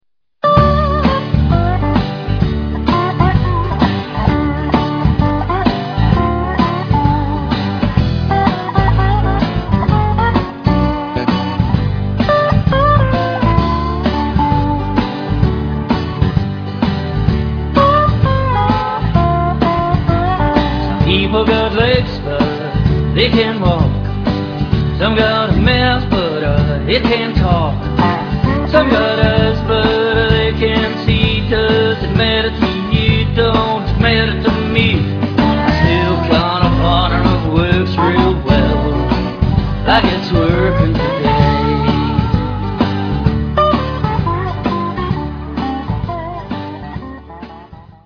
Real Audio Stereo Clip                                         Mono Wave Clip
Intro.  D - G x 4 -Em7-A7 -F#7-Bm-G-A- D-G-D-G-D-G-D-G.
Repeat D-G and fade away.